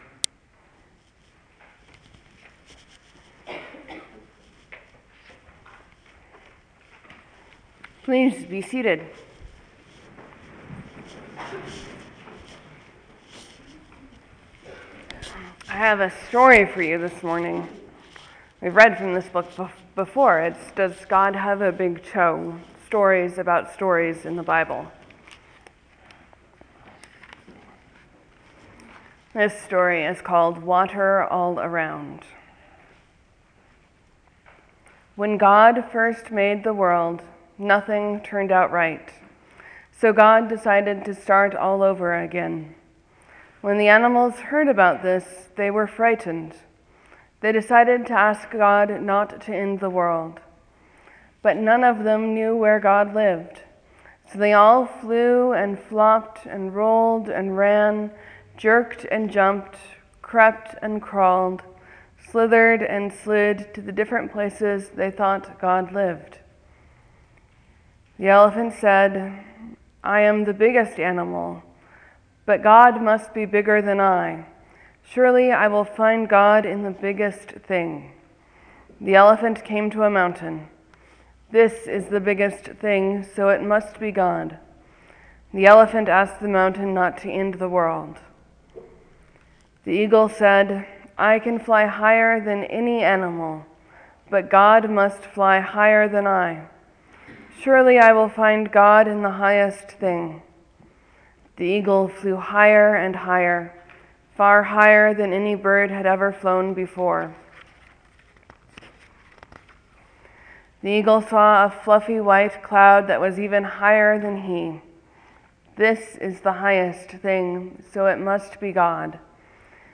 Morsels & Stories: I read from “Does God Have a Big Toe?”
Sermons: Jesus takes Peter, James, and John up the mountain for the Transfiguration.